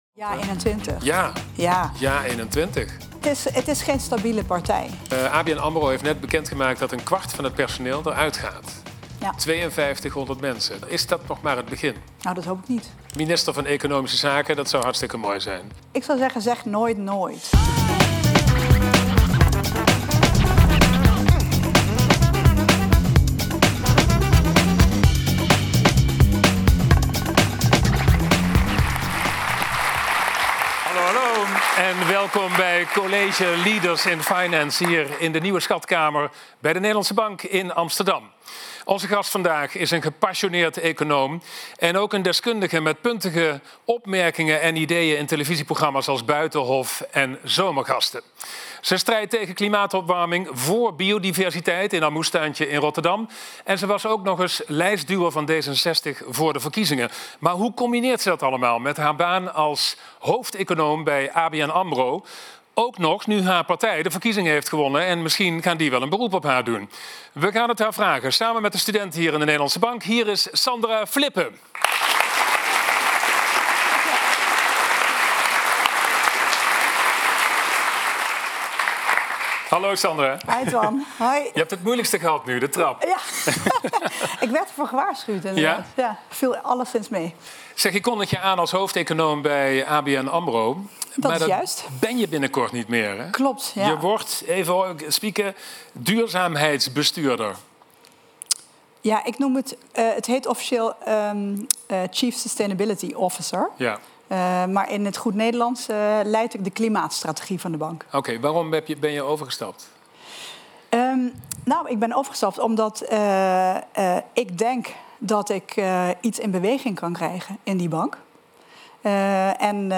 College Leaders in Finance verbindt studenten en young professionals met de top van de financiële en economische wereld. Samen met Twan Huys gaan we in gesprek met ministers, topbankiers, economen en andere sleutelfiguren uit de sector. De colleges vinden plaats in De Nieuwe Schatkamer van De Nederlandsche Bank, waar publiek niet alleen kan luisteren maar ook hun eigen vragen mag stellen.